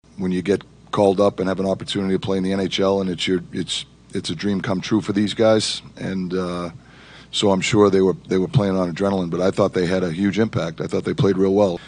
Rookie forwards Rutger McGroarty and Ville Koivunen were in the lineup yesterday, skating on the top two lines, and Sullivan said they fit right in.